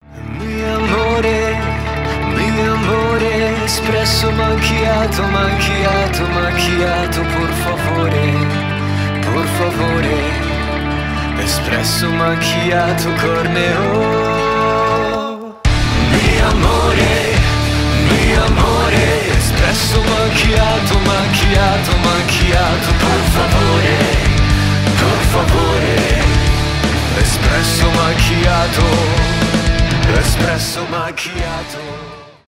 метал
рок